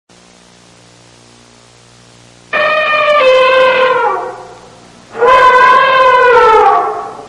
Tiếng con Voi gầm
Tiếng động vật 167 lượt xem 10/03/2026
Download file mp3 tiếng con voi gầm hung dữ.